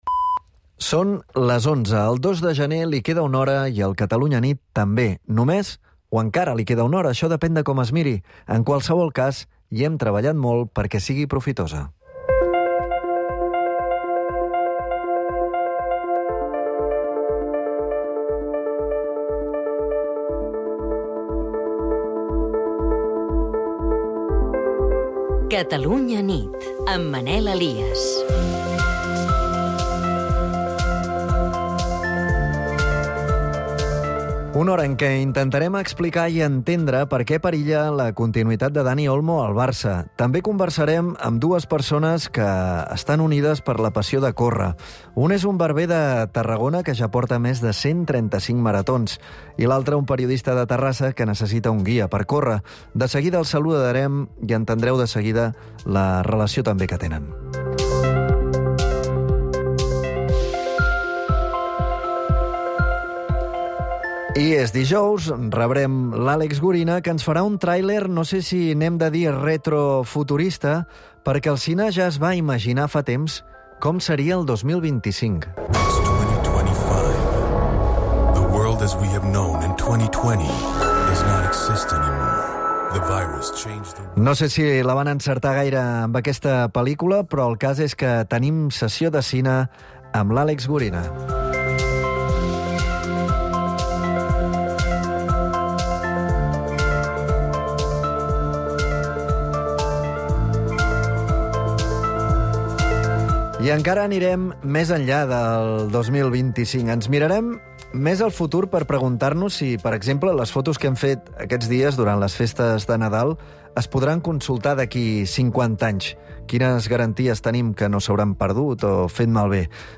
Informatius